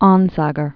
(ôngər), Lars 1903-1976.